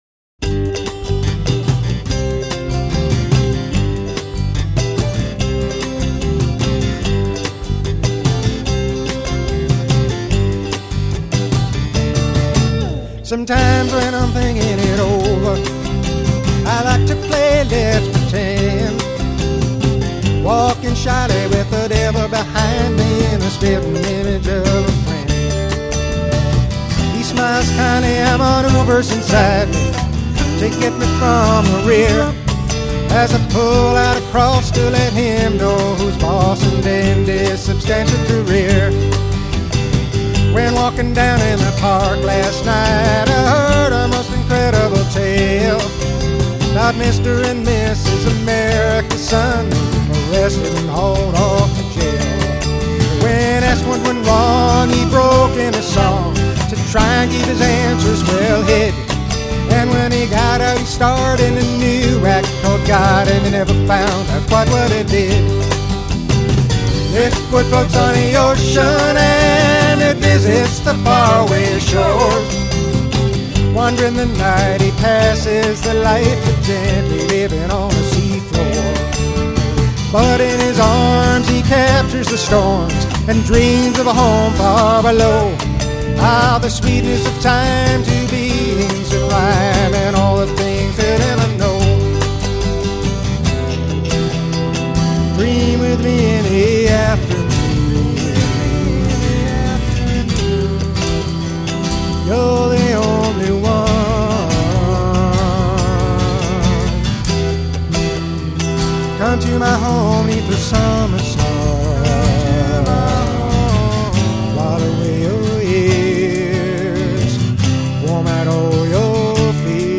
Vintage Live & Rehearsal Recordings